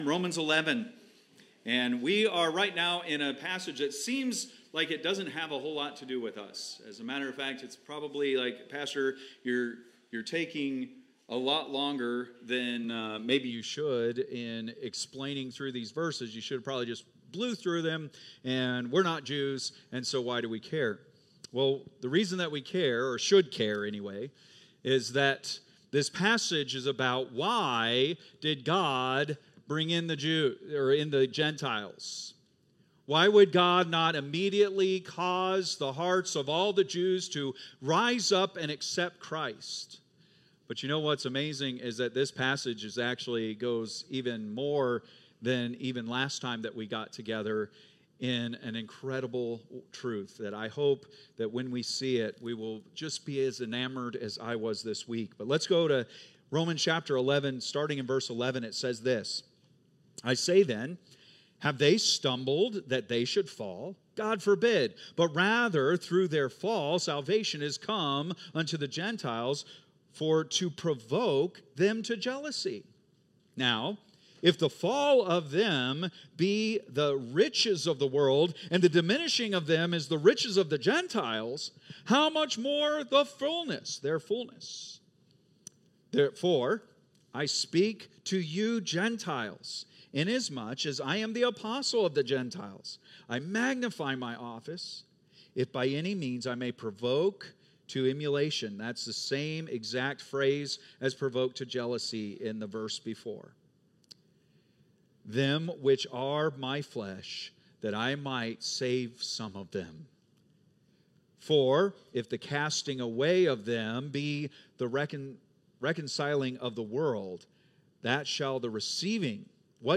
Date: March 30, 2025 (Sunday Morning)